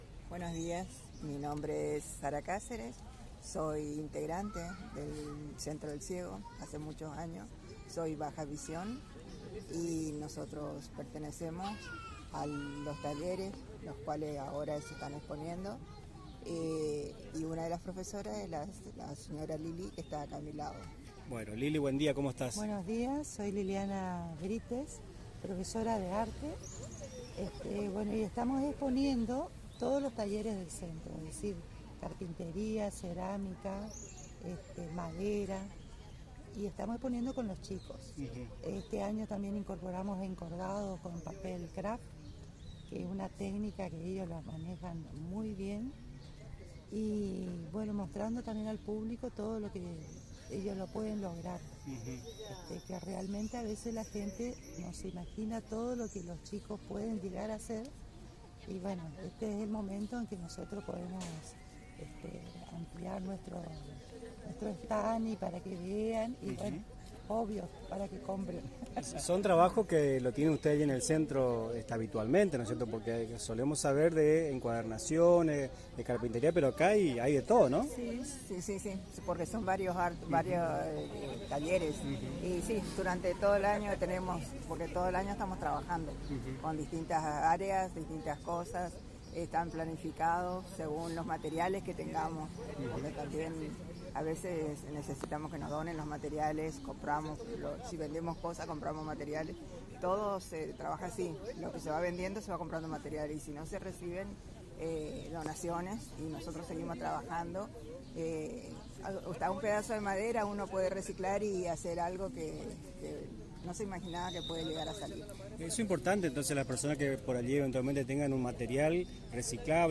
El equipo de Nuestras Mañanas estuvo presente para informar a la audiencia de Radio Tupambaé sobre la actividad y los trabajos exhibidos.